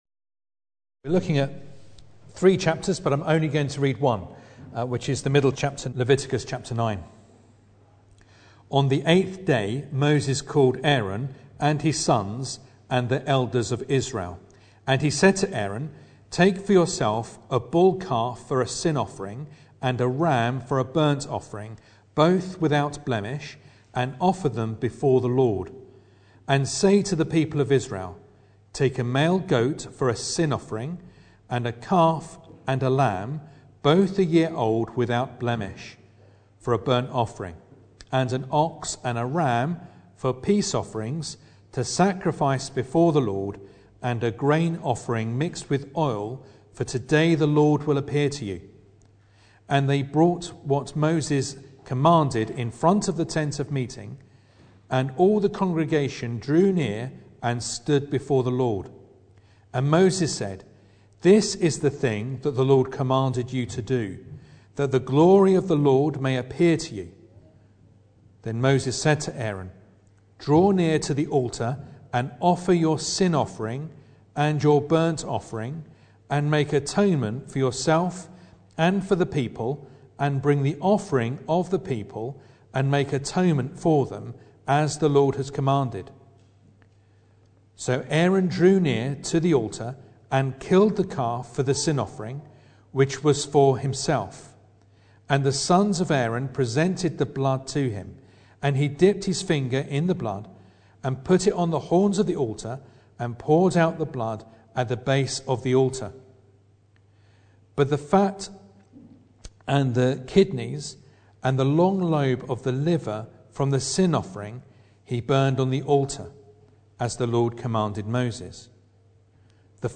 Leviticus 8-10 Service Type: Sunday Evening Bible Text